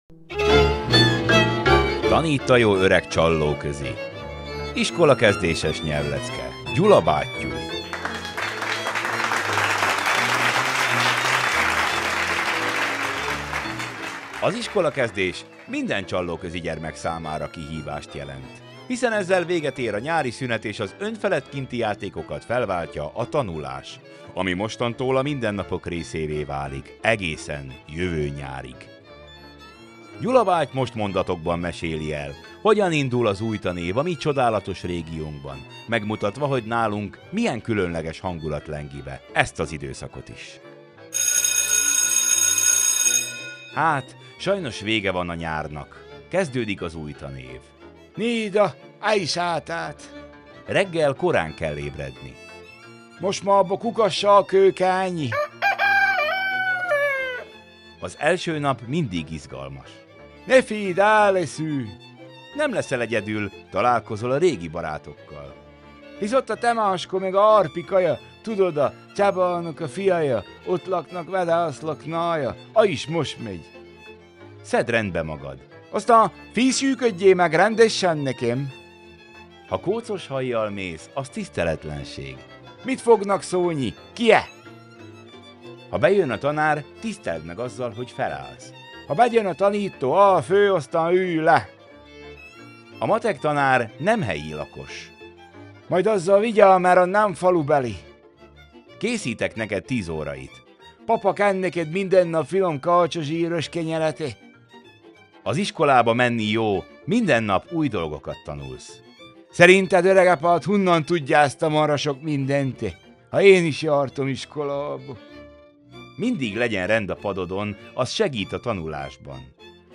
Zene:
A nyelvleckék: